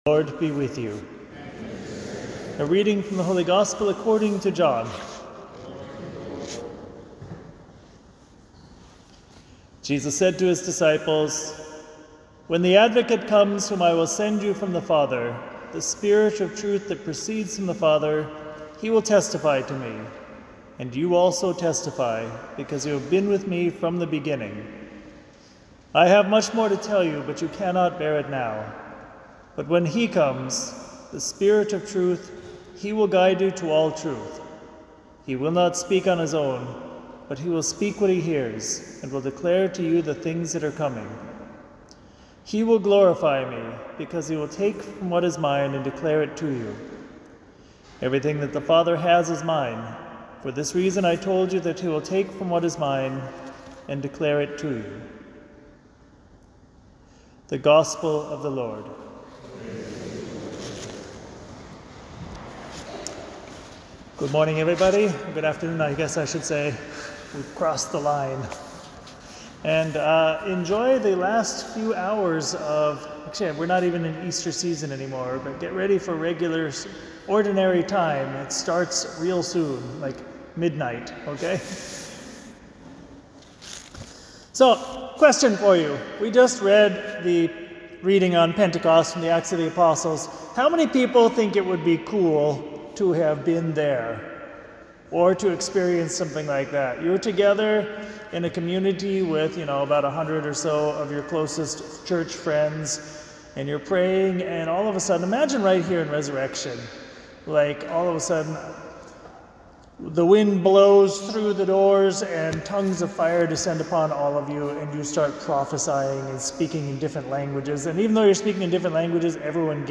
FEN_Homily_Pentecost.mp3